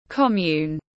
Xã tiếng anh gọi là commune, phiên âm tiếng anh đọc là /ˈkɒm.juːn/.
Commune /ˈkɒm.juːn/